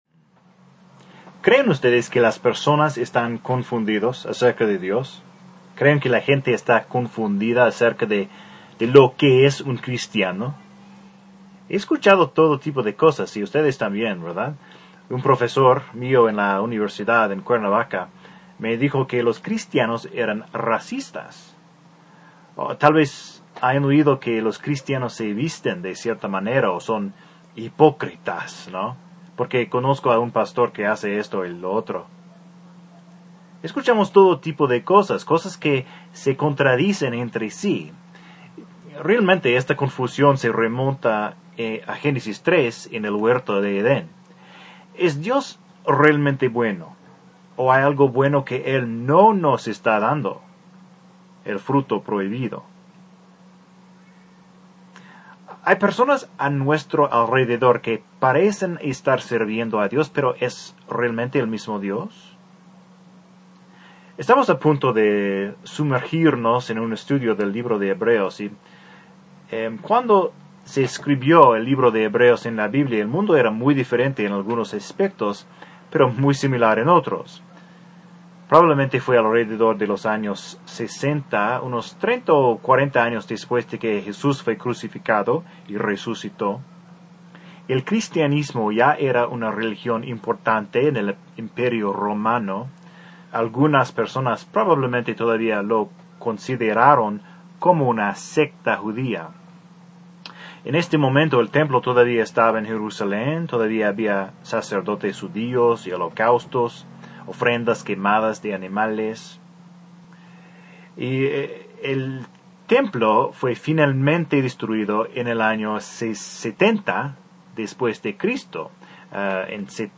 Un sermón de Hebreos 1:1-2.